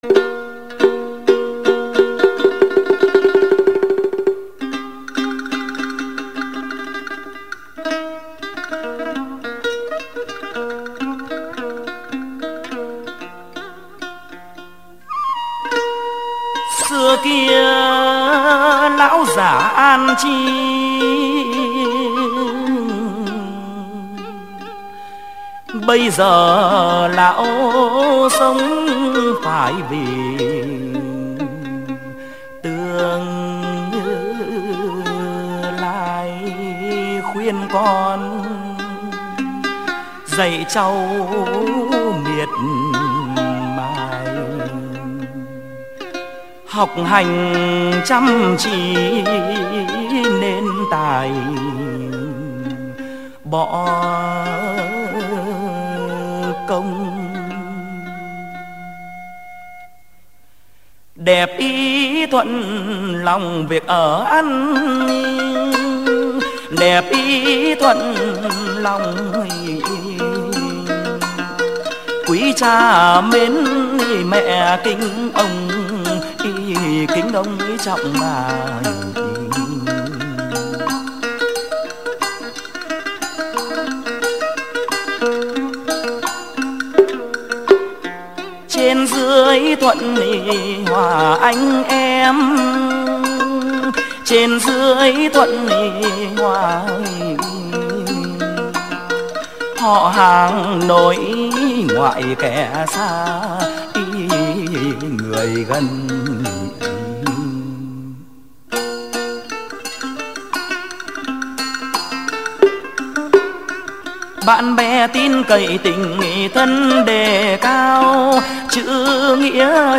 thuộc thể loại Hát văn - Hát chầu văn.